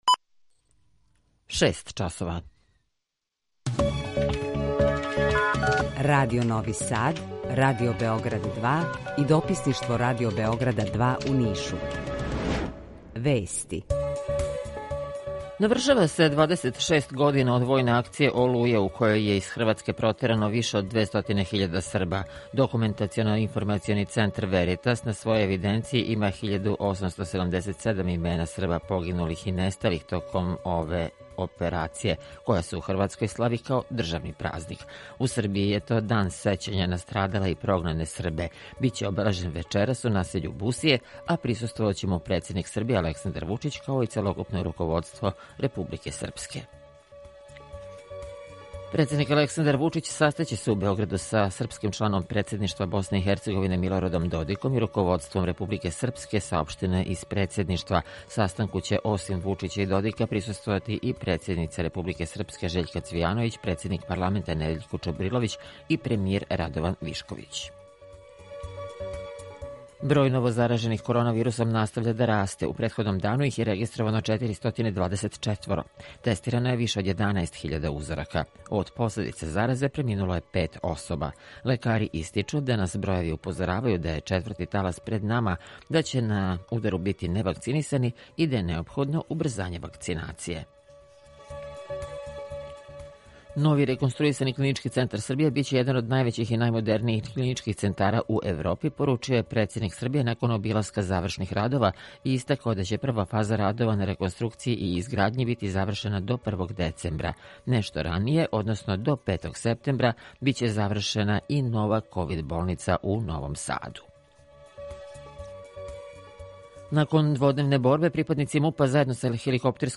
Укључење Косовске Митровице
Јутарњи програм из три студија
Jутарњи програм заједнички реализују Радио Београд 2, Радио Нови Сад и дописништво Радио Београда из Ниша. Cлушаоци могу да чују најновије информације из сва три града, најаве културних догађаја, теме које су занимљиве нашим суграђанима без обзира у ком граду живе.
У два сата, ту је и добра музика, другачија у односу на остале радио-станице.